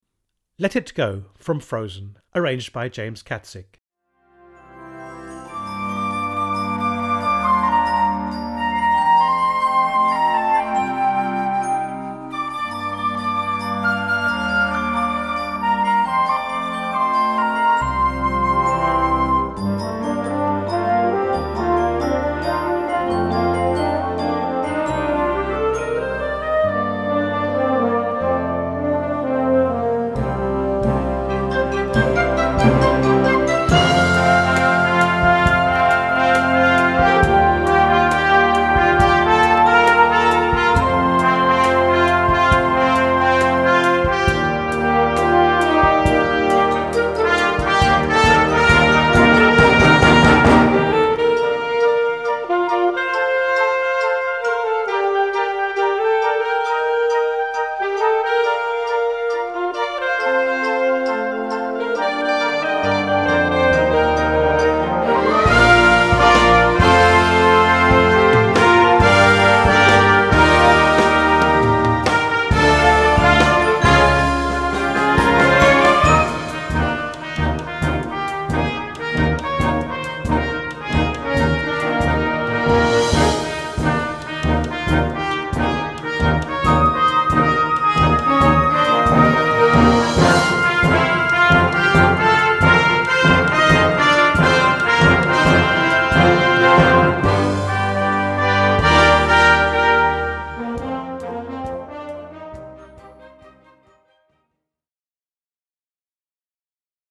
Category: Music from the MOVIES - Grade 2.0
Here's a terrific setting for younger players.